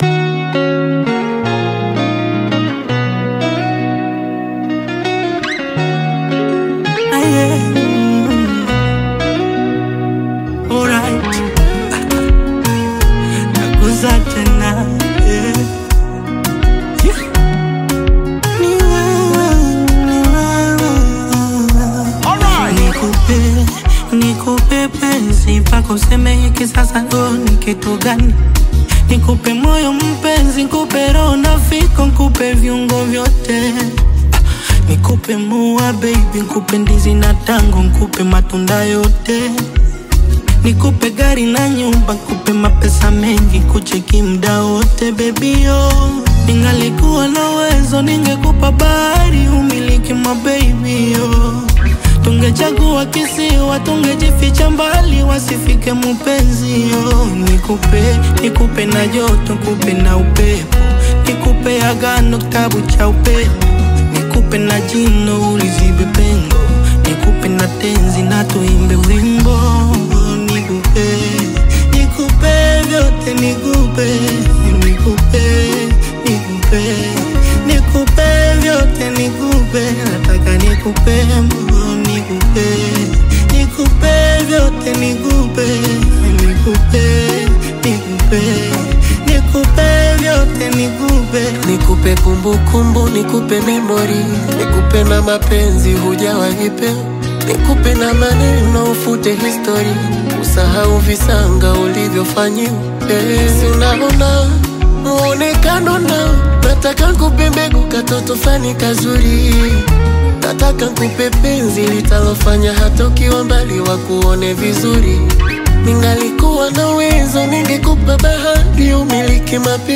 ” a romantic song centered on giving